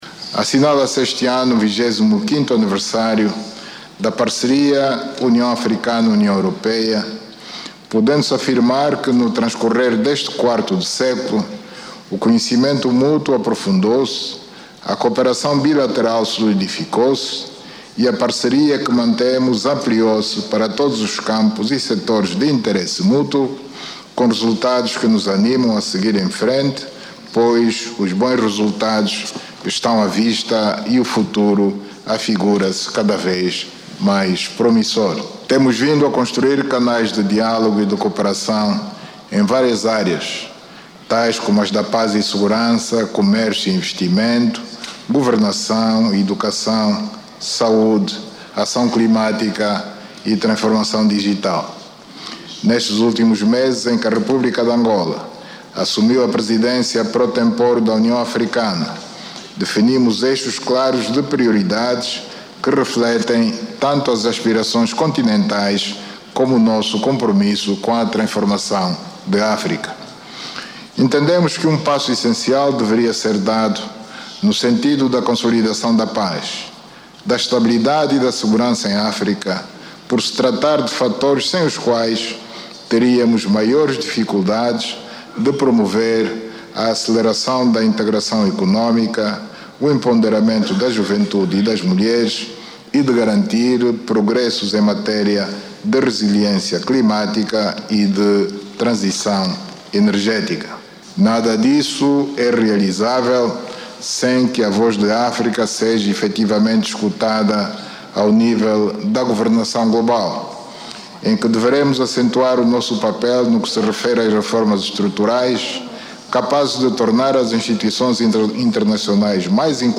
No discurso de abertura, o Presidente angolano e da União Africana, João Lourenço, destacou os 25 anos de cooperação entre os dois blocos continentais, sublinhando que este período tem registado ganhos para ambas as partes.